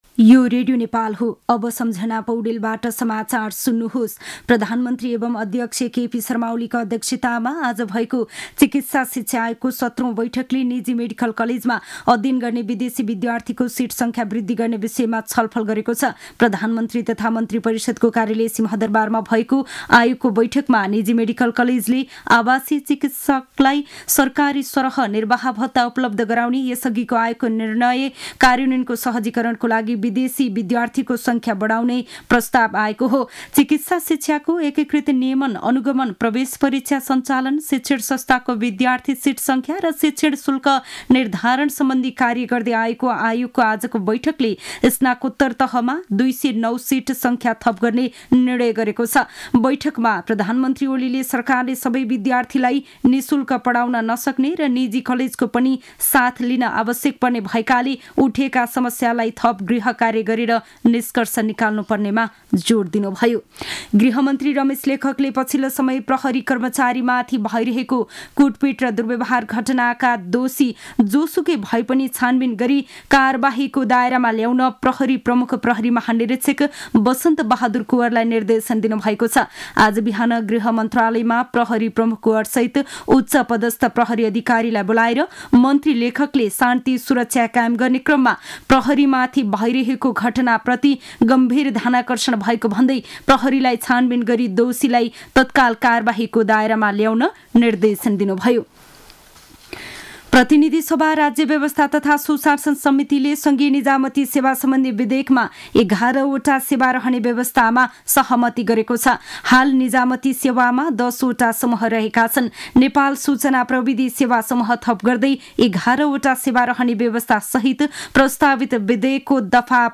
दिउँसो ४ बजेको नेपाली समाचार : १ फागुन , २०८१
4-pm-news-1-4.mp3